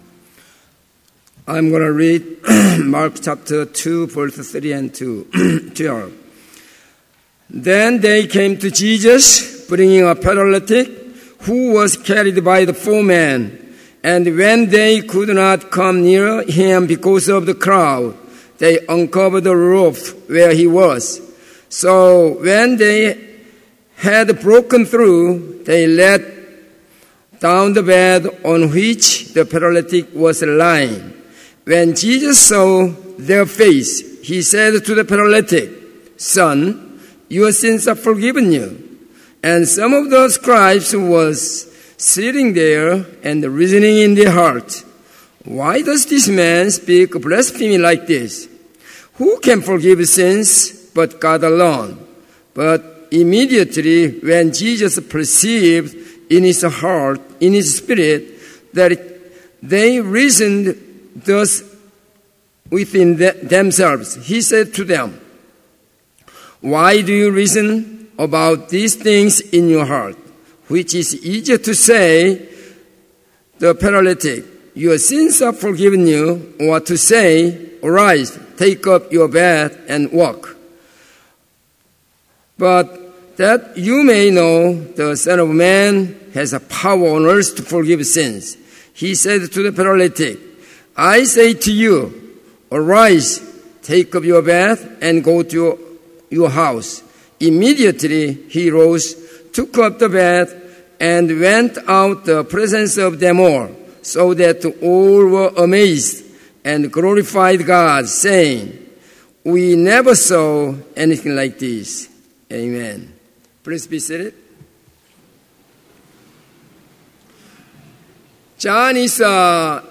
Complete service audio for Chapel - May 2, 2018